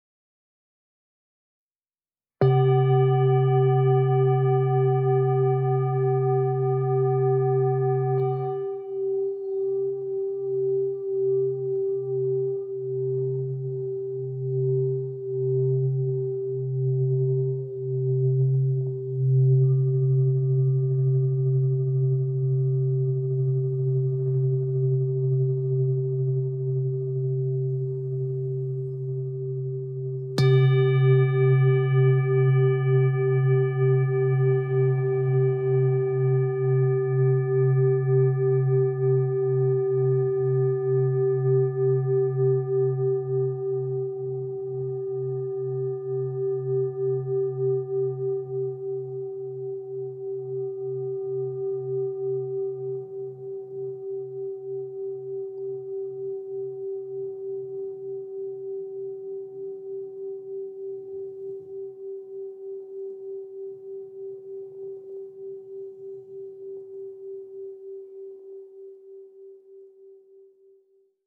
Meinl Sonic Energy Universal Series Singing Bowl - 1700 g (SB-U-1700)
Ob einzeln oder im Set gespielt, diese Schalen erzeugen einen faszinierenden, vielschichtigen Klang, der tief im Inneren resoniert. Mit einem sanften Anschlag entfalten sie reiche, komplexe Töne, begleitet von schwebenden Obertönen, die sich leicht im Raum ausbreiten und im Körper spürbar werden. Sobald der Klang ertönt, bleibt er lange nachklingen – auch noch eine Minute später ist ein sanftes Vibrieren zu spüren.